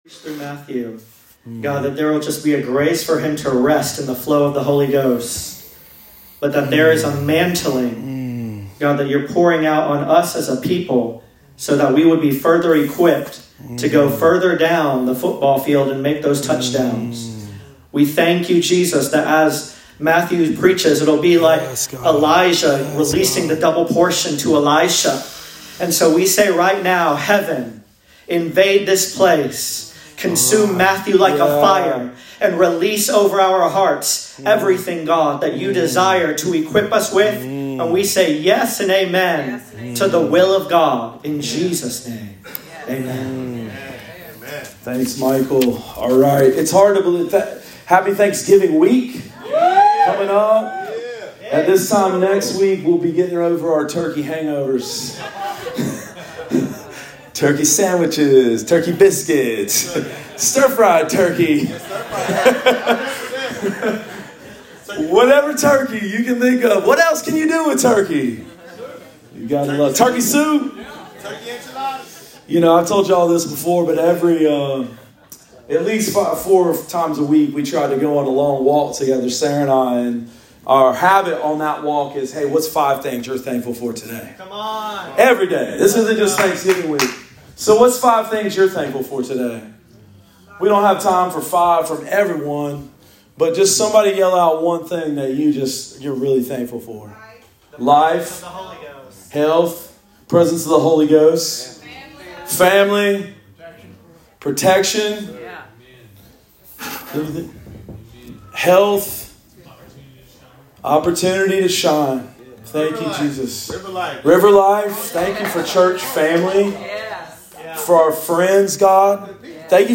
Sermon of the Week: 11-19 – RiverLife Fellowship Church